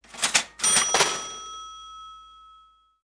01_purchase.mp3